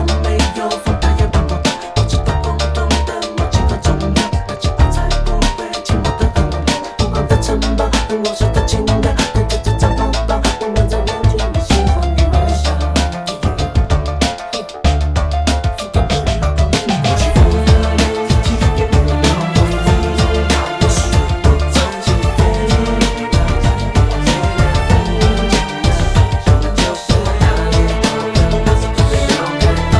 高音质立体声带和声消音伴奏